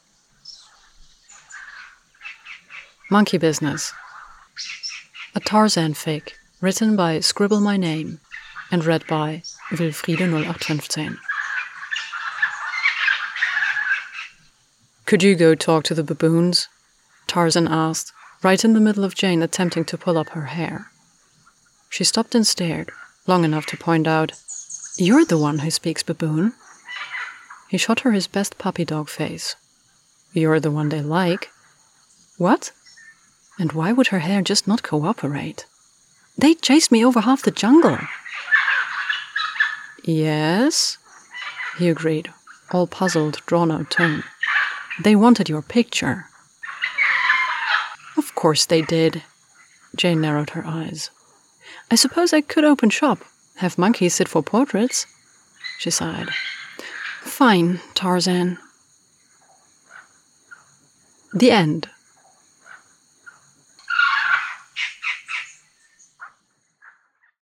with effects